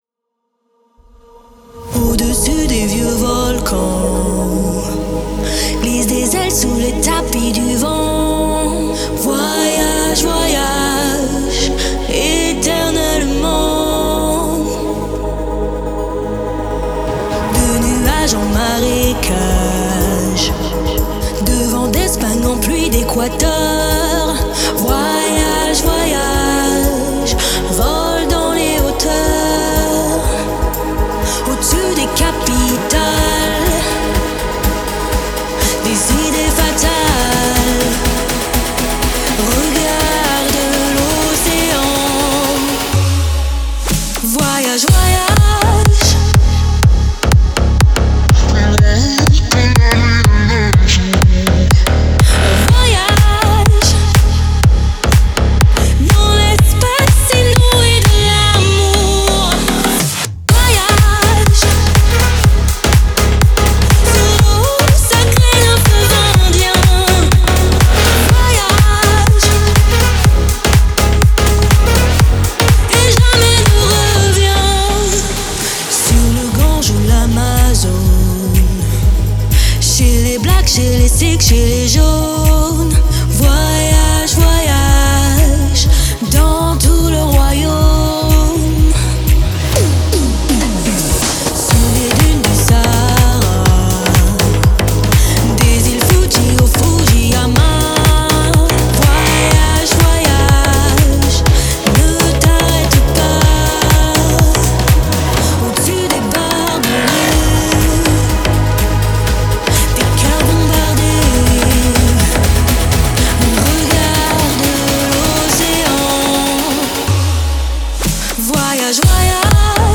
это динамичная танцевальная композиция в жанре EDM